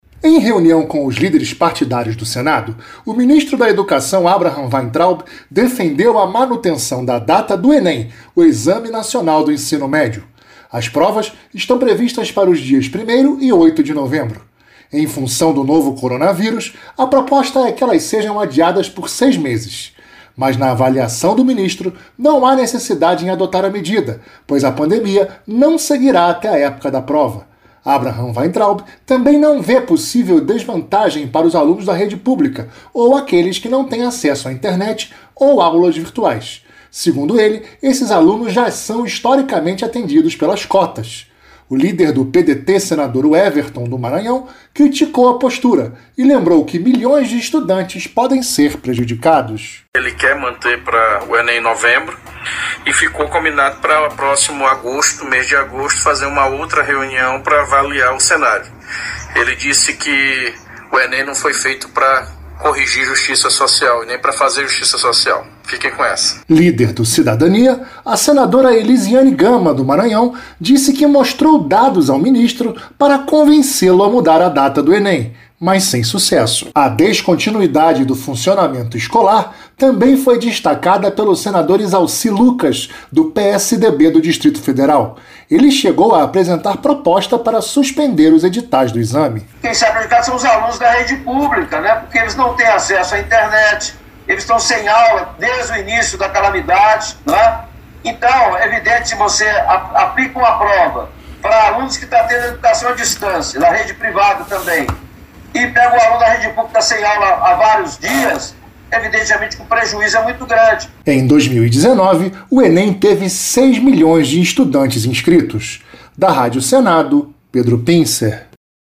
Os detalhes com o repórter